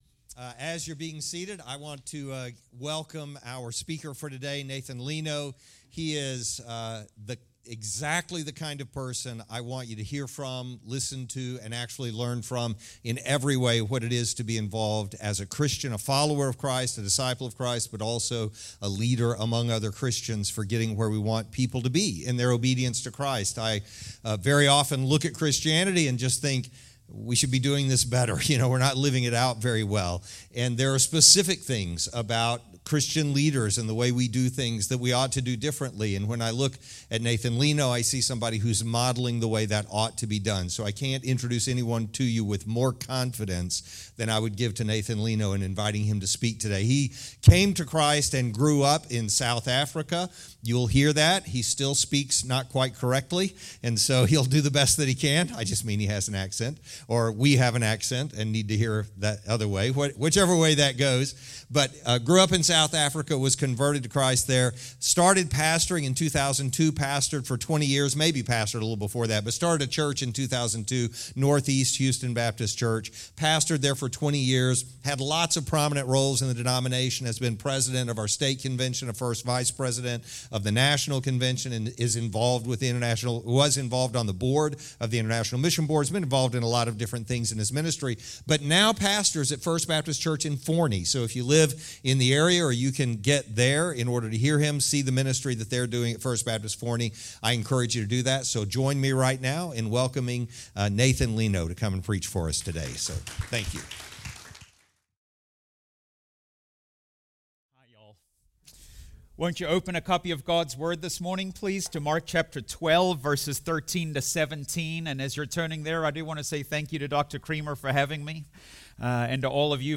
Criswell College Chapel Service.